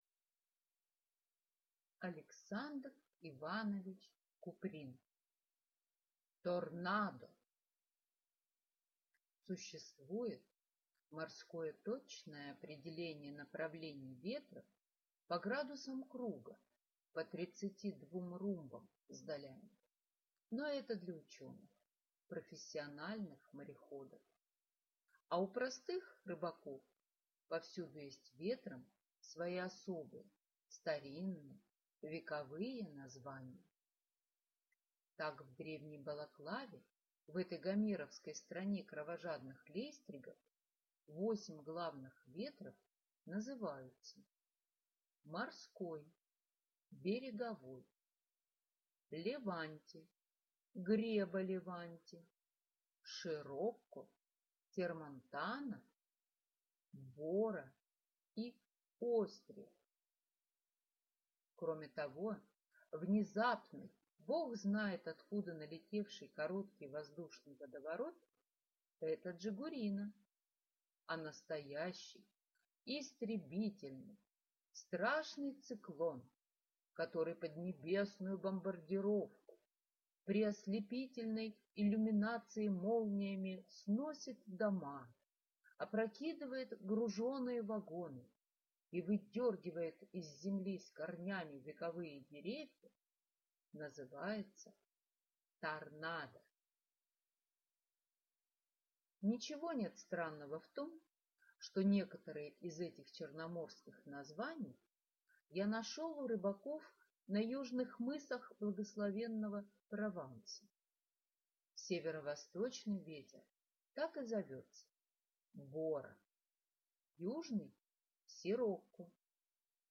Аудиокнига Торнадо | Библиотека аудиокниг